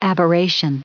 7_aberration.ogg